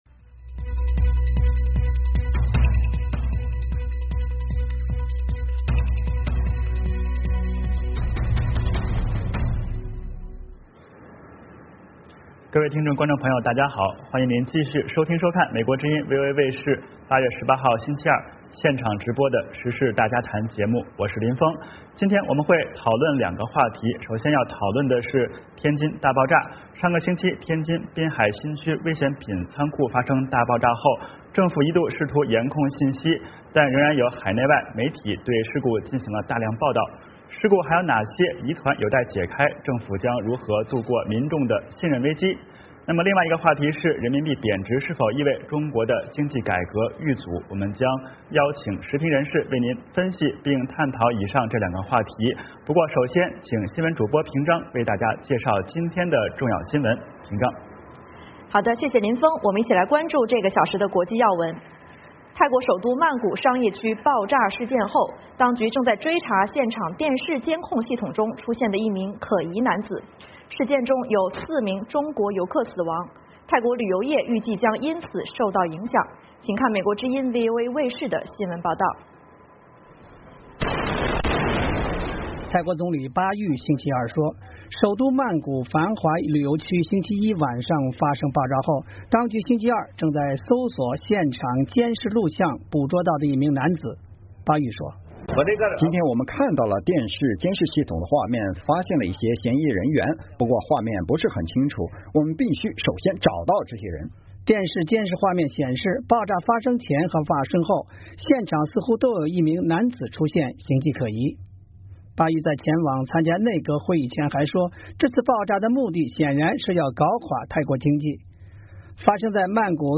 VOA卫视第二小时内容包括重要国际新闻以及时事大家谈。《时事大家谈》围绕重大事件、热点问题、区域冲突以及中国内政外交的重要方面，邀请专家和听众、观众进行现场对话和讨论，利用这个平台自由交换看法，探索事实。